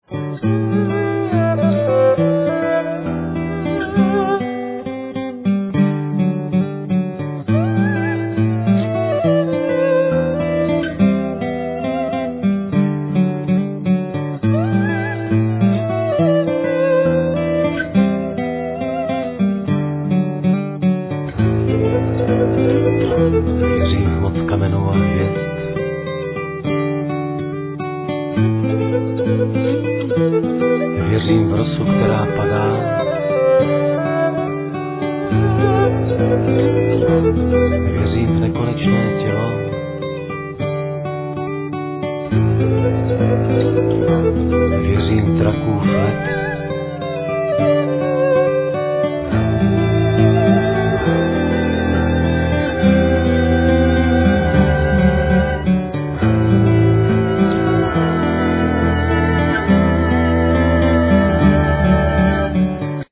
Guitars, Voice, Percussions
Saxophone
Accordion
Viola